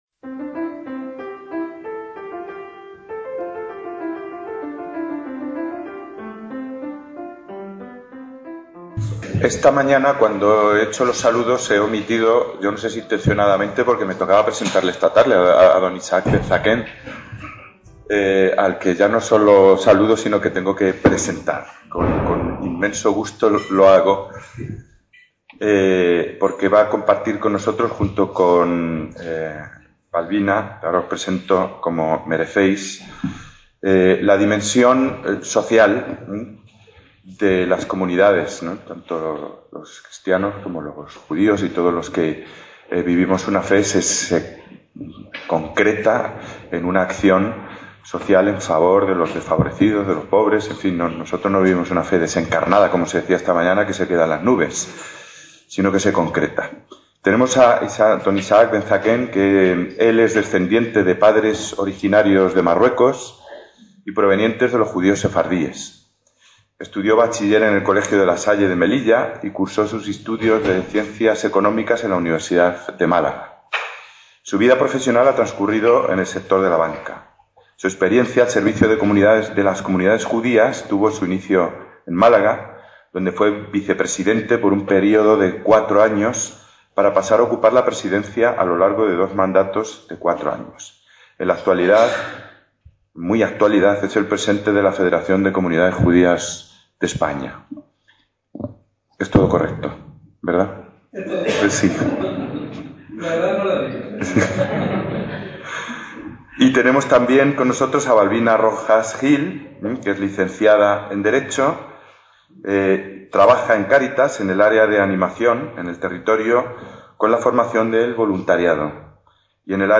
II JORNADA DE AMISTAD JUDEO-CRISTIANA - La sesión de la tarde de la Jornada, celebrada en la Sala de Conferencias del Claustro de la Catedral de Toledo